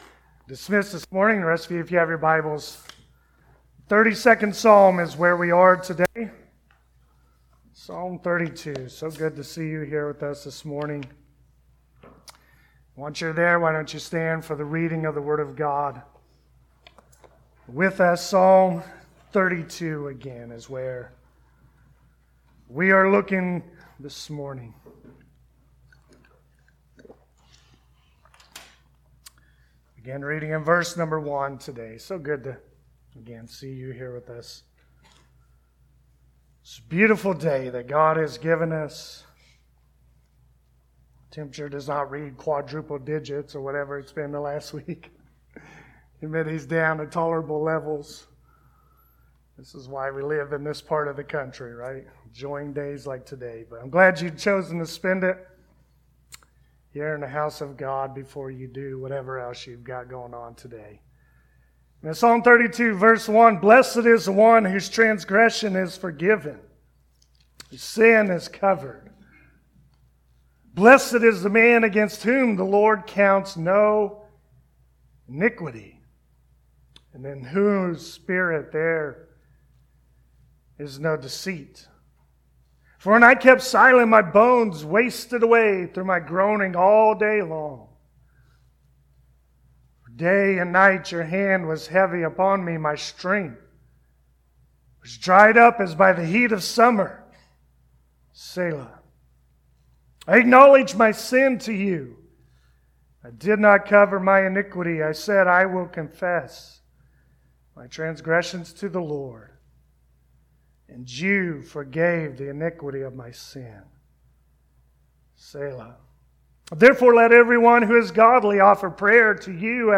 Passage: Psalm 32 Service Type: Sunday Morning David learns through his sinful actions that God does forgive all our sins.